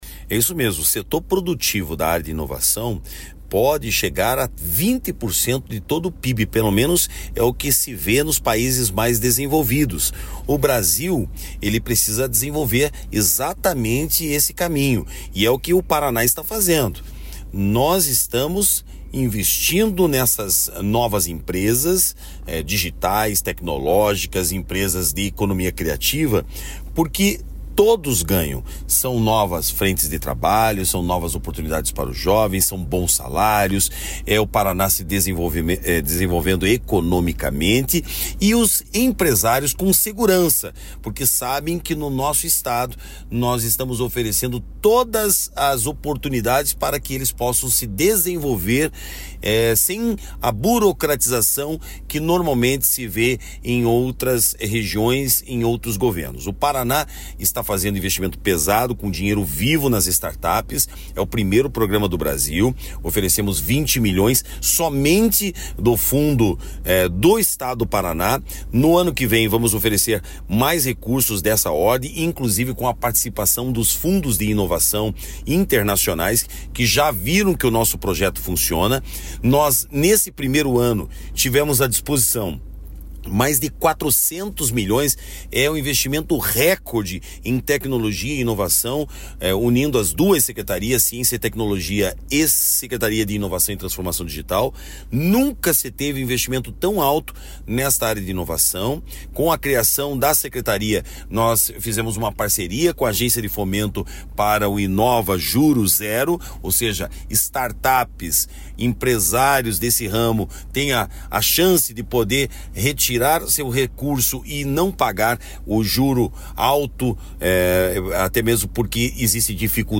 Sonora do secretário da Inovação, Modernização e Transformação Digital, Marcelo Rangel, sobre o Paraná incentivar startups e pequenas empresas inovadoras